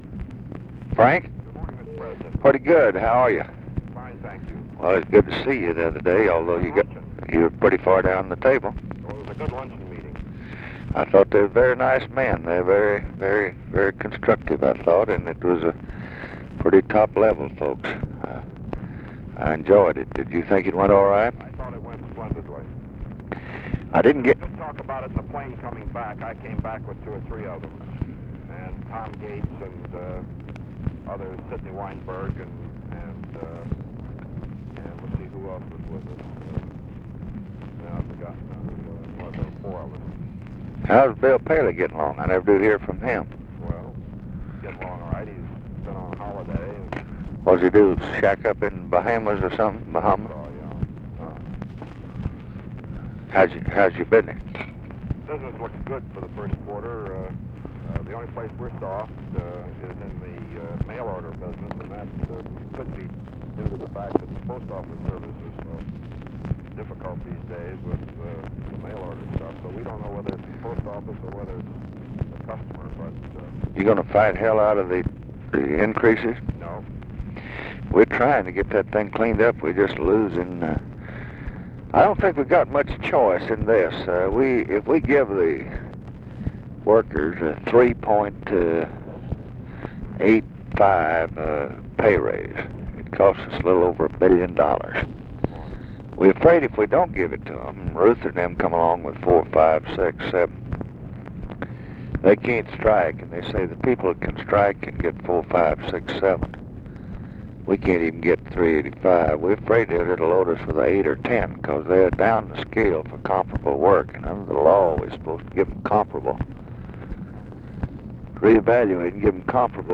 Conversation with FRANK STANTON, January 7, 1967
Secret White House Tapes